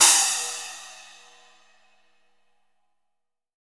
-10 SPLASH.wav